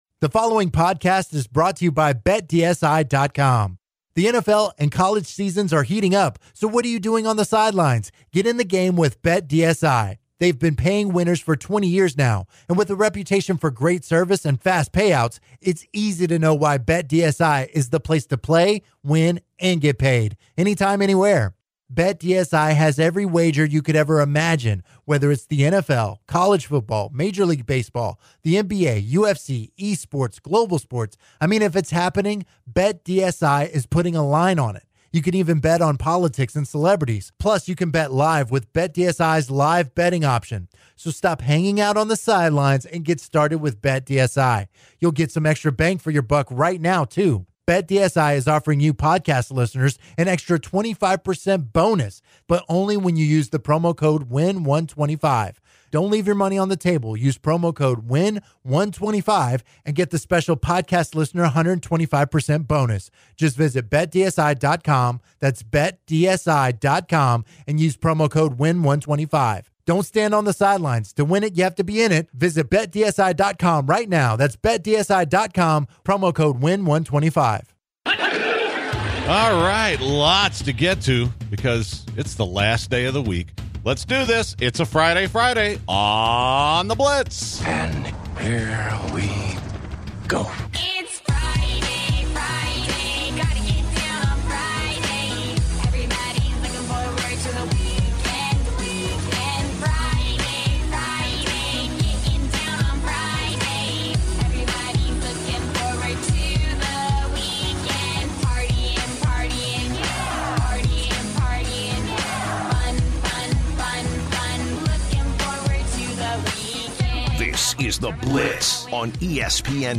After venting about Houston traffic a bit, they take a caller to dive further into the gambling talks.